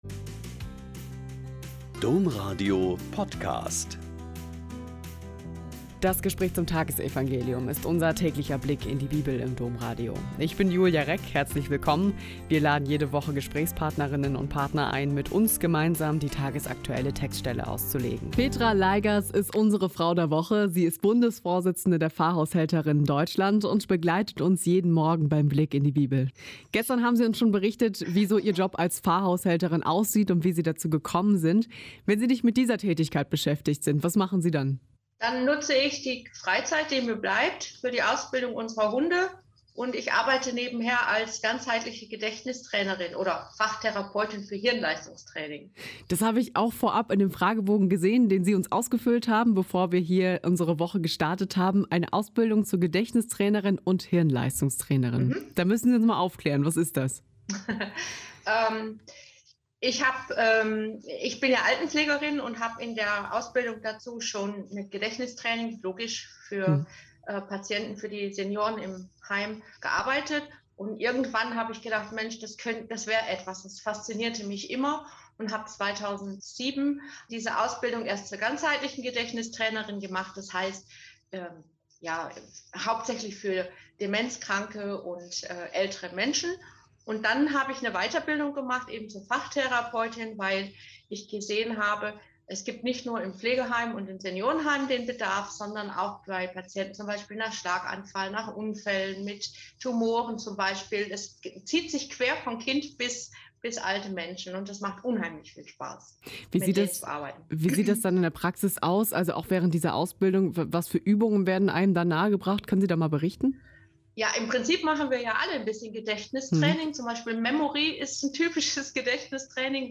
Mk 5,21-43 - Gespräch